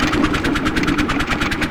c_spiker_atk1.wav